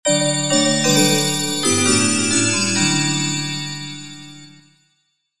短いフレーズで作成してあるのでメールなどの通知音にぴったりです。